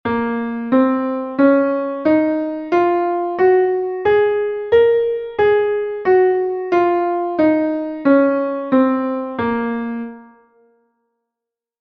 Sibm (audio/mpeg)